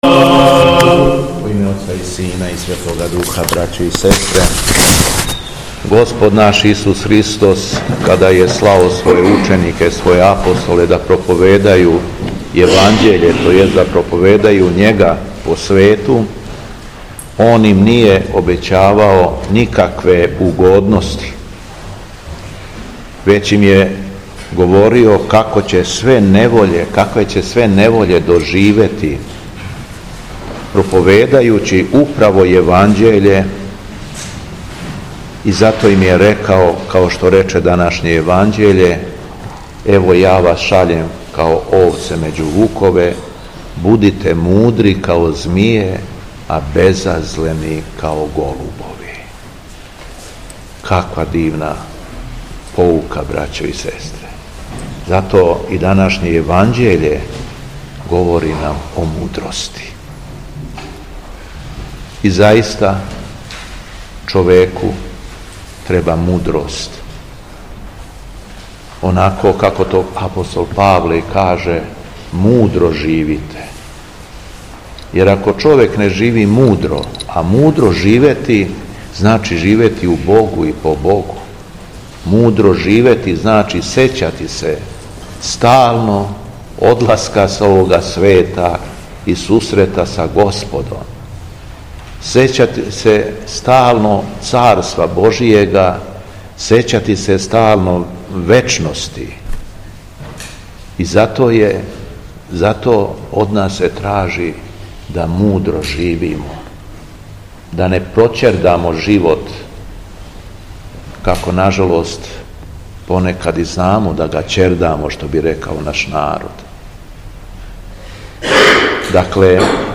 У четвртак 16. јануара 2025. године, Његово Високопресвештенство Митрополит шумадијски Г. Јован служио је Свету Литургију у Старој Цркви у Крагујевцу...
Беседа Његовог Високопреосвештенства Митрополита шумадијског г. Јована